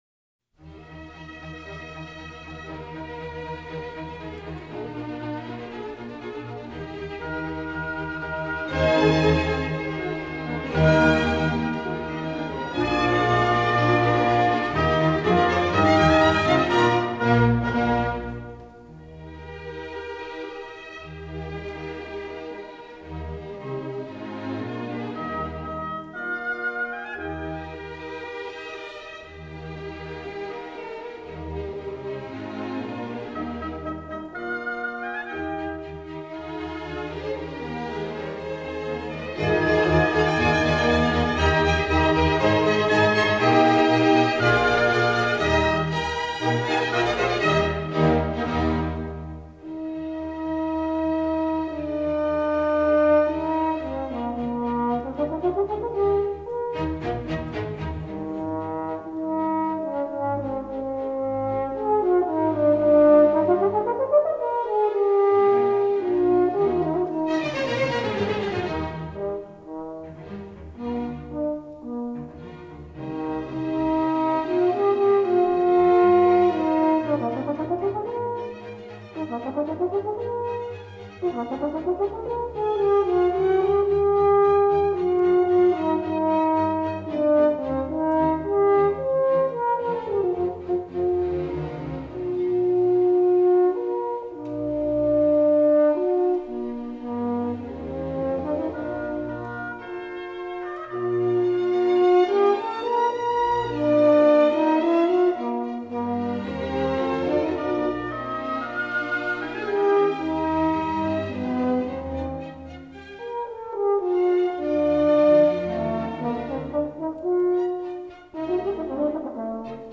- 호른 협주곡 제2번 마장조 K.417
제1악장 Allegro maestoso      제2악장 Andante      제3악장 Rondo